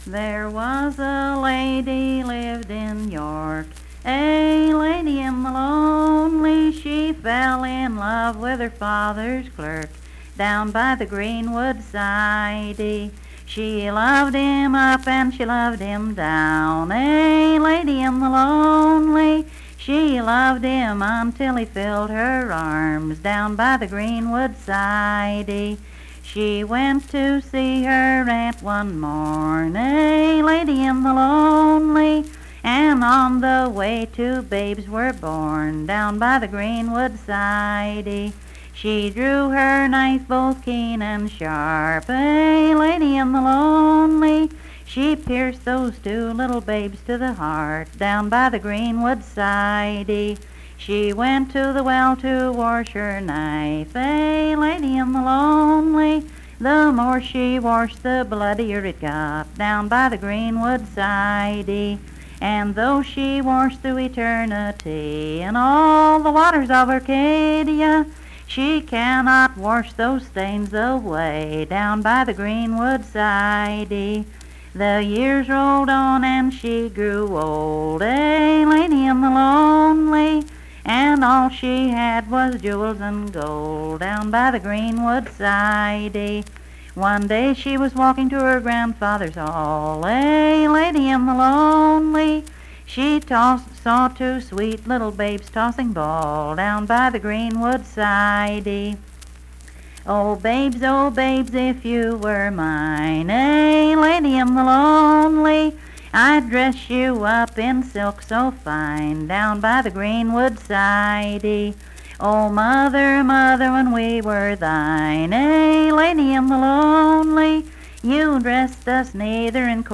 Unaccompanied vocal music
Performed in Coalfax, Marion County, WV.
Voice (sung)